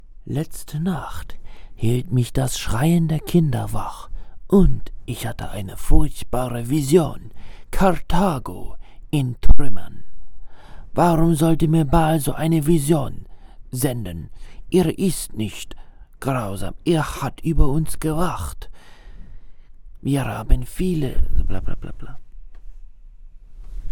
rode nt1, my recordings sound cheap
I dont know if it's the microphone or room treatment, or the fact that im not using any effects. My voice in my recordings sounds thin. My mic is set to mono.